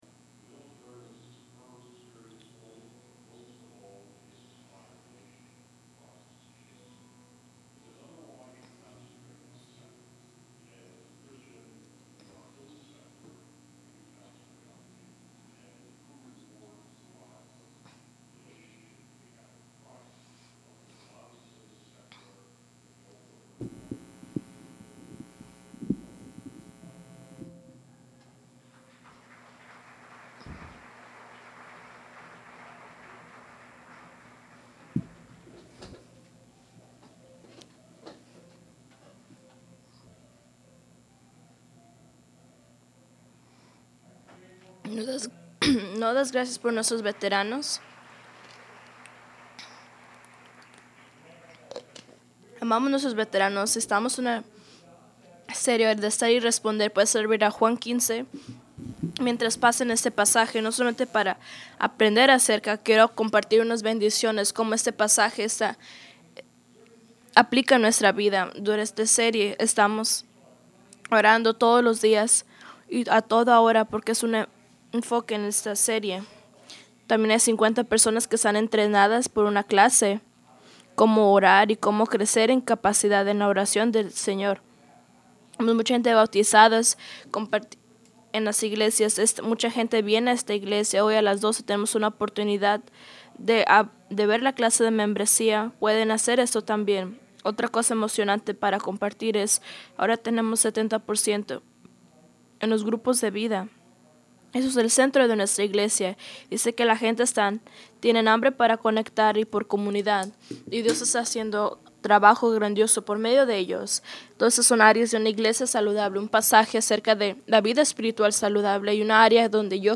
Grace Community Church: Auburn, WA > “FRIENDSHIP WITH JESUS”